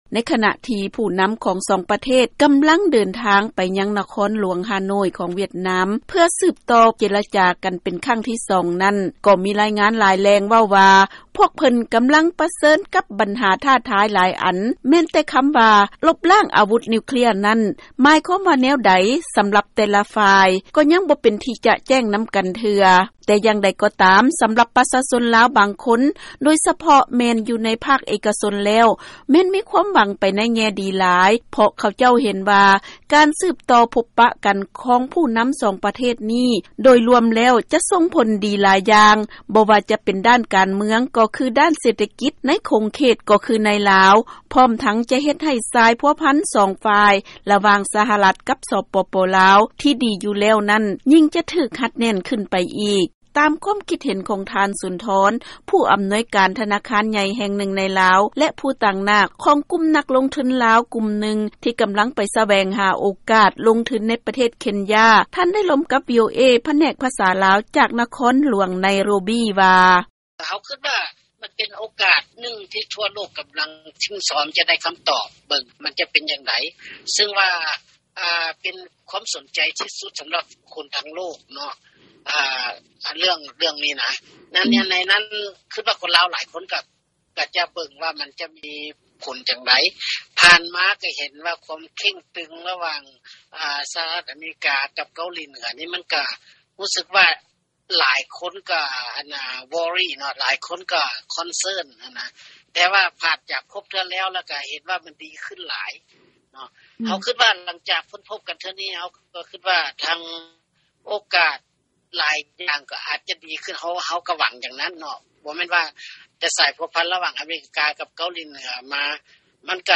ເຊີນຟັງການສຳພາດຊາວລາວກ່ຽວກັບກອງປະຊຸມສຸດຍອດລະຫວ່າງ ທ. ທຣຳ ແລະທ. ກິມ